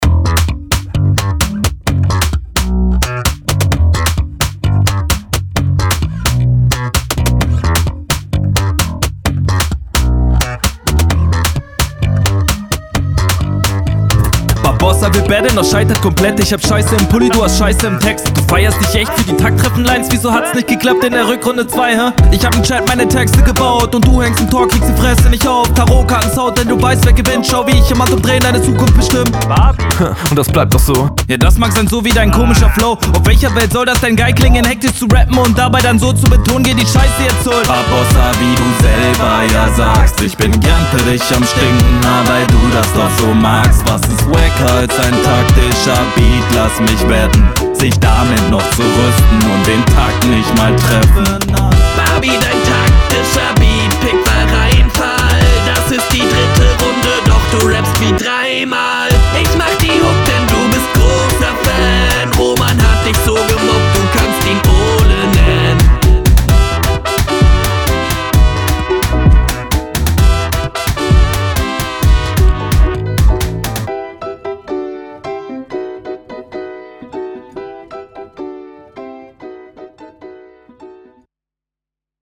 Flow kommt krasser.